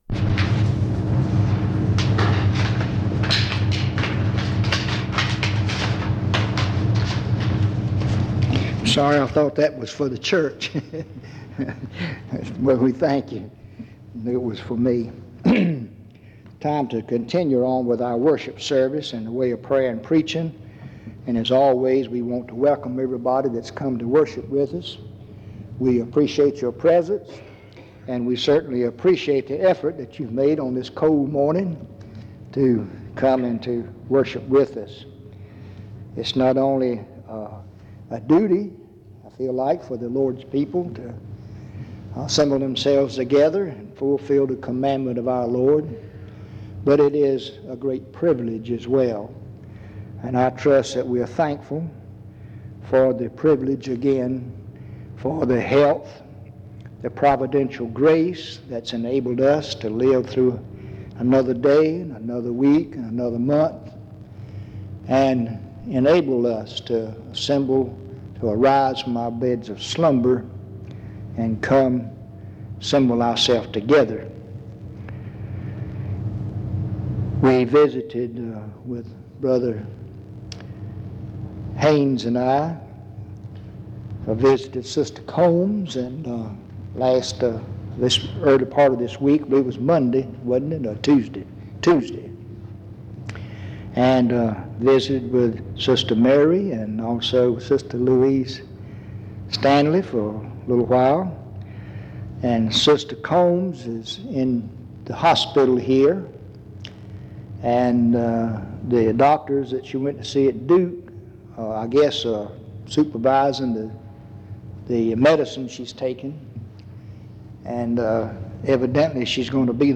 Em Collection: Reidsville/Lindsey Street Primitive Baptist Church audio recordings Miniatura Título Data de carga Acesso Ações PBHLA-ACC.001_033-A-01.wav 2026-02-12 Baixar PBHLA-ACC.001_033-B-01.wav 2026-02-12 Baixar